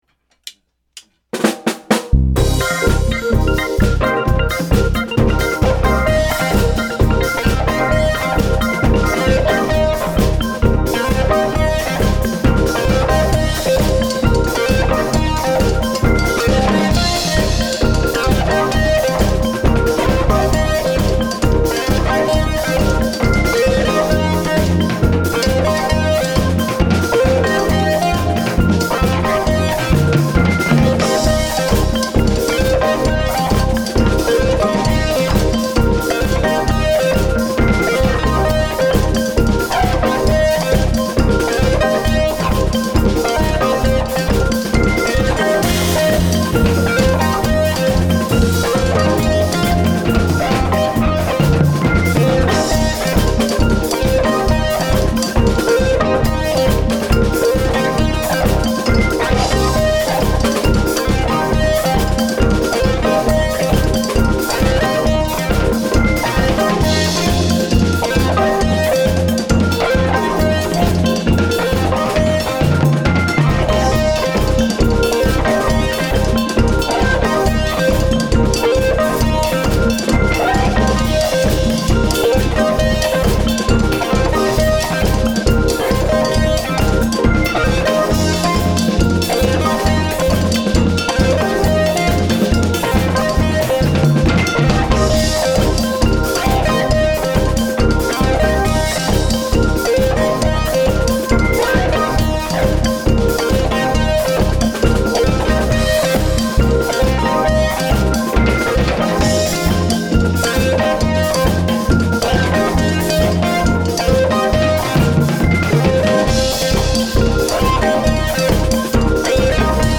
Percussive Rhythms Affecting Intense Spiritual Expression